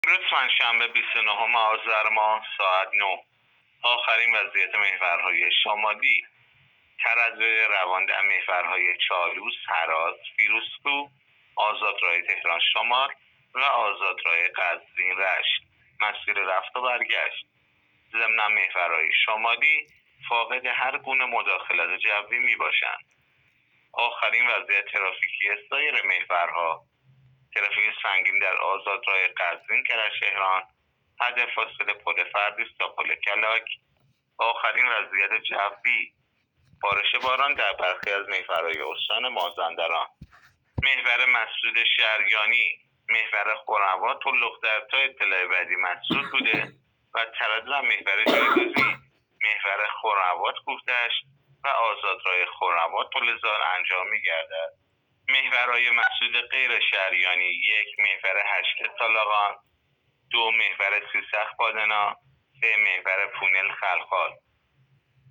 گزارش رادیو اینترنتی از آخرین وضعیت ترافیکی جاده‌ها تا ساعت ۹ بیست و نهم آذر؛